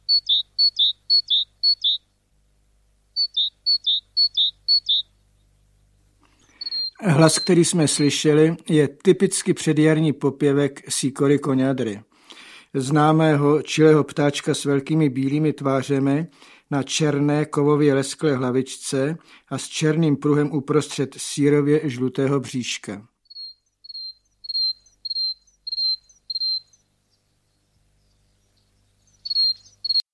Komentované nahrávky nejběžnějších a nejzajímavějších ptáků žijících v blízkosti člověka. Naučte se rozeznávat druhy ptáků žijících na uzemí ČR podle zpěvu a nejen to: získejte vědomosti o jejich vzhledu, místě hnízdění, stravě a rozmnožování.